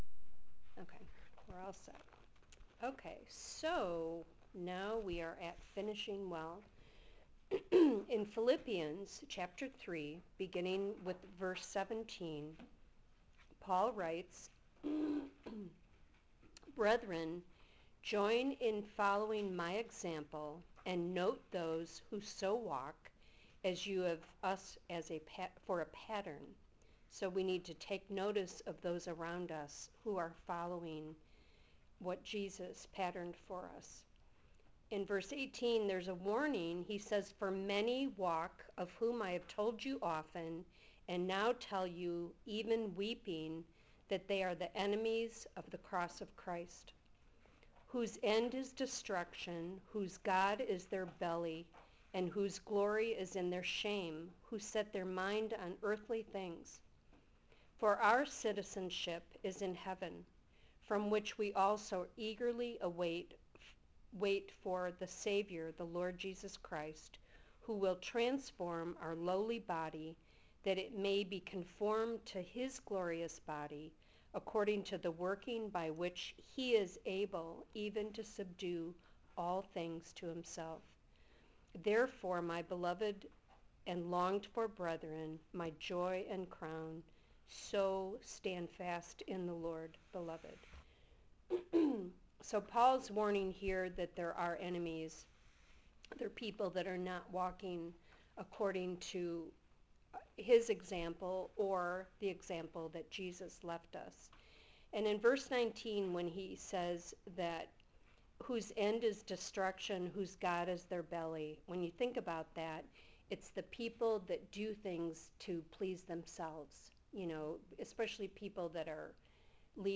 004 - Finishing Well (2025 Women’s Conference)